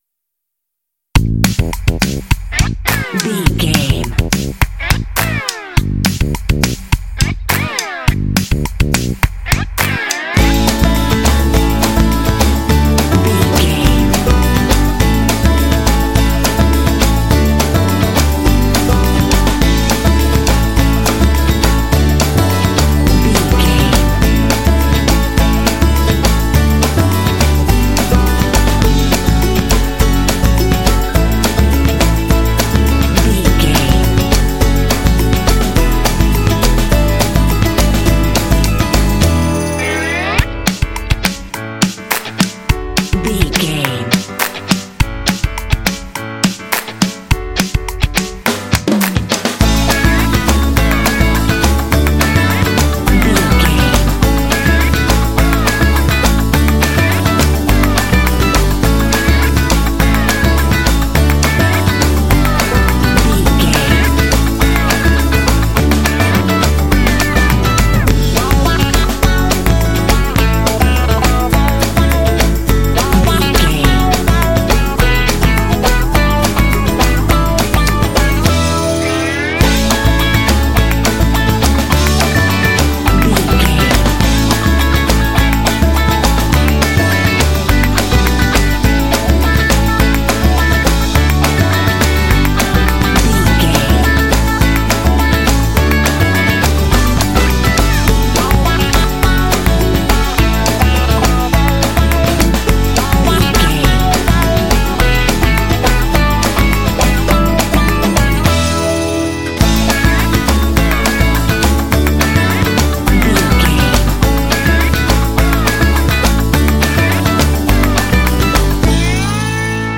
This tune is super cheerful and full of energy.
Ionian/Major
Fast
bouncy
groovy
bright
bass guitar
electric guitar
drums
acoustic guitar
rock
pop
alternative rock
indie